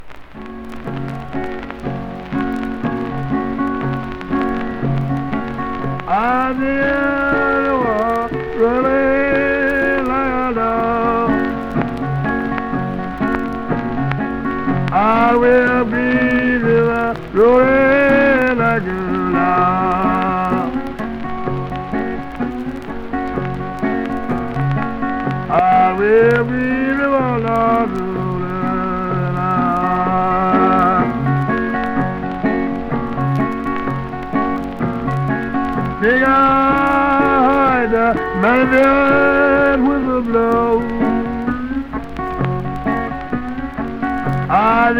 Blues　USA　12inchレコード　33rpm　Mono